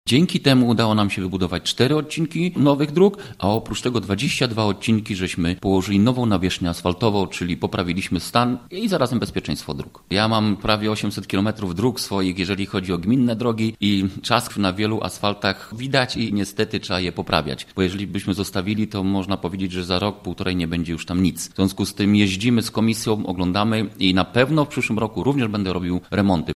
– To wpłynęło na dobrą ocenę roku 2020 w zakresie remontu dróg w gminie Żary – mówi wójt Leszek Mrożek.